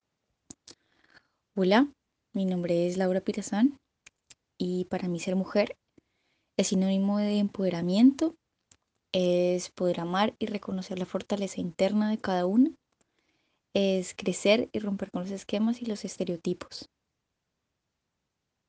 Narración oral acerca de lo que significa ser mujer. La narradora es bogotana y considera que ser mujer es sinónimo de empoderamiento, reconociendo la fortaleza de cada una, rompiendo esquemas y estereotipos. El testimonio fue recolectado en el marco del laboratorio de co-creación "Postales sonoras: mujeres escuchando mujeres" de la línea Cultura Digital e Innovación de la Red Distrital de Bibliotecas Públicas de Bogotá - BibloRed.